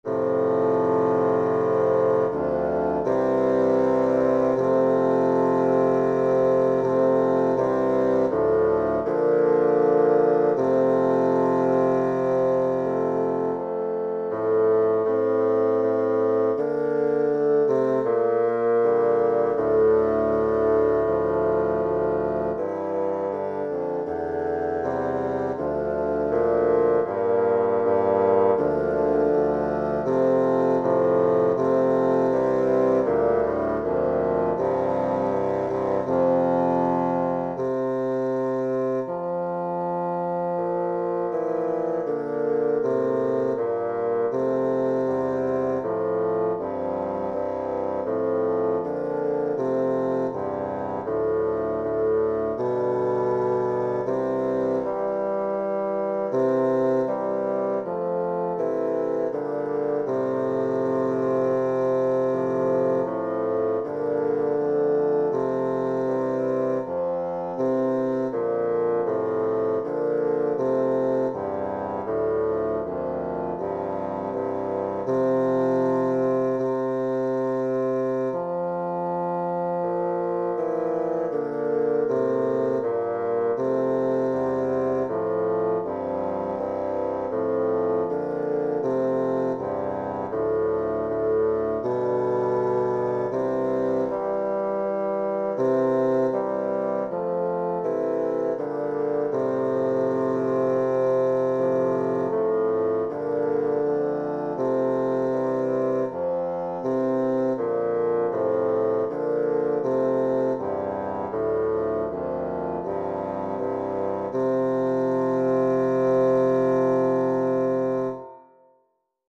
Voicing: Bassoon Quartet